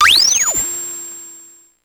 Index of /90_sSampleCDs/300 Drum Machines/Electro-Harmonix Spacedrum
Drum21.wav